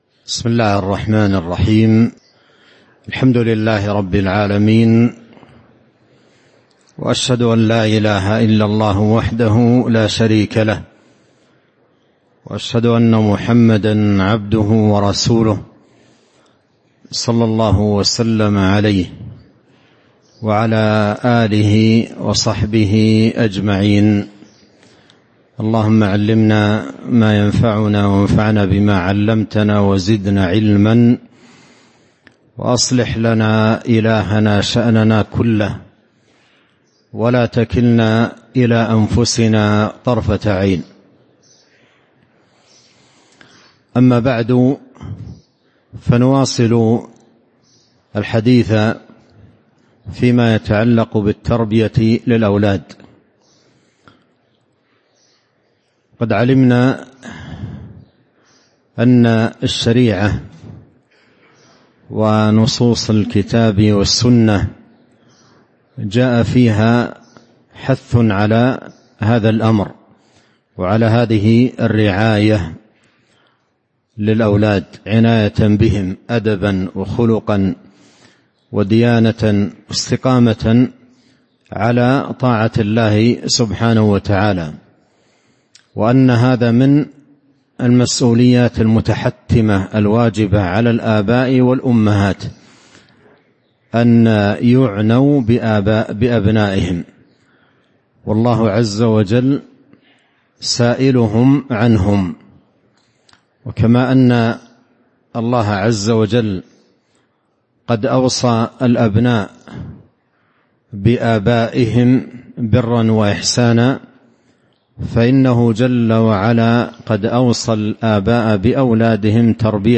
تاريخ النشر ٢٤ ربيع الأول ١٤٤٥ هـ المكان: المسجد النبوي الشيخ